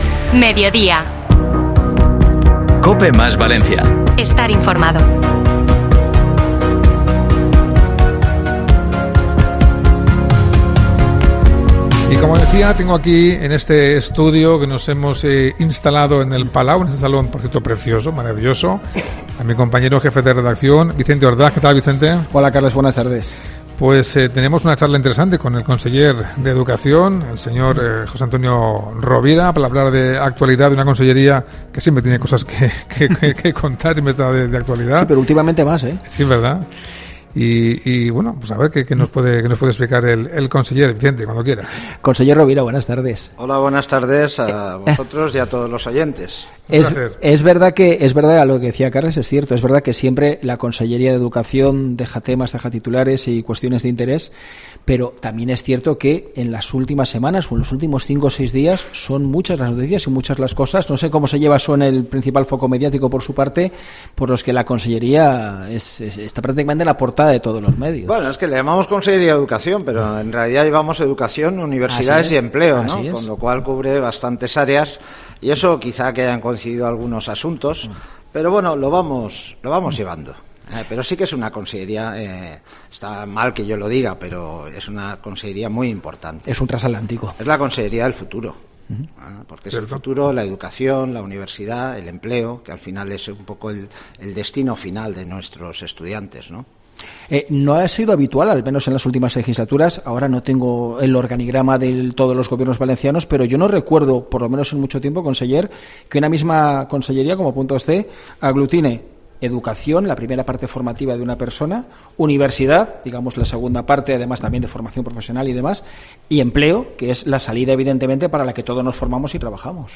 El Conseller visita los micrófonos de COPE Valencia para hablar de todos los temas relacionados con la educacion de los valencianos